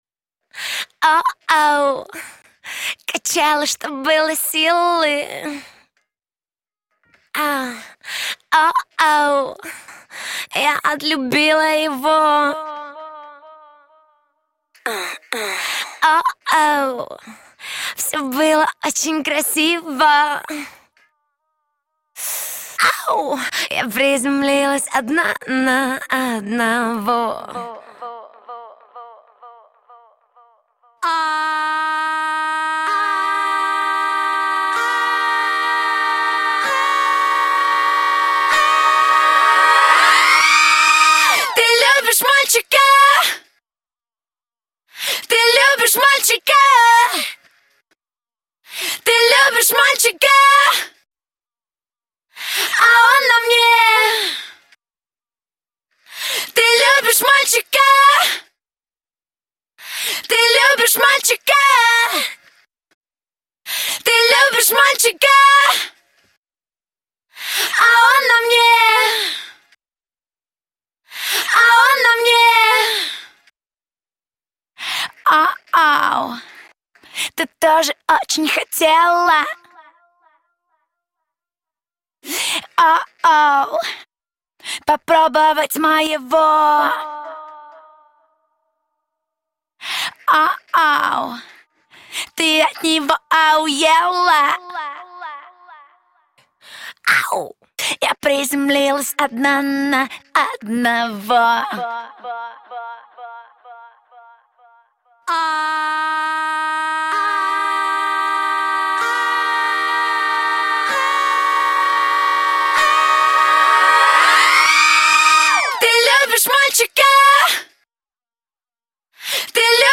Категория: Скачать Русские акапеллы